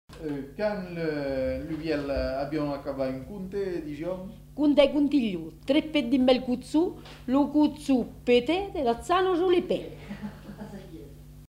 Aire culturelle : Périgord
Genre : forme brève
Type de voix : voix de femme
Production du son : récité
Classification : formulette